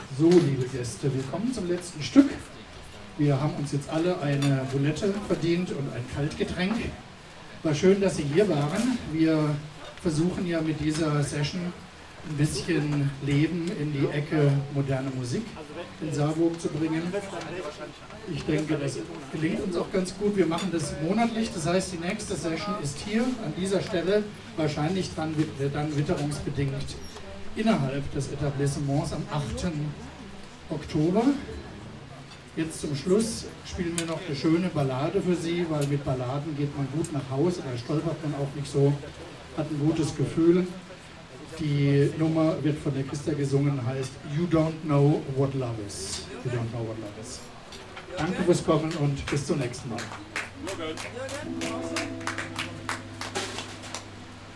19 - Verabschiedung.mp3